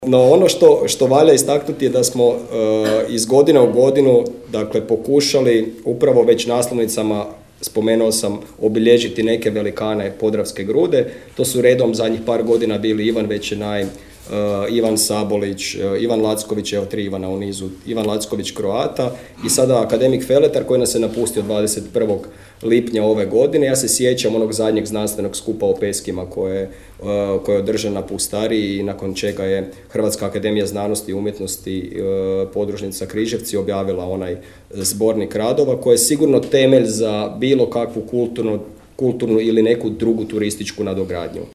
Također, održana je i promocija 49. Podravskog zbornika 2023.